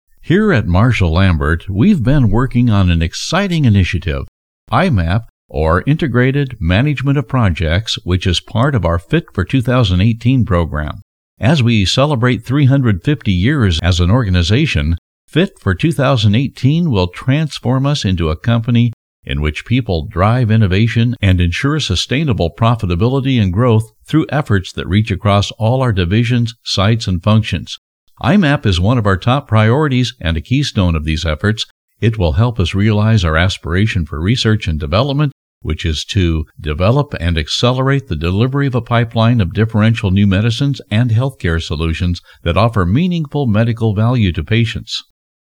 Male
English (North American)
Adult (30-50), Older Sound (50+)
Versatile voice from soft sell to hard sell with characters and pacing to match the script.
Deep and serious to silly and sophisticated.
Friendly tone for children's projects to corporate tone to Texas cowboy.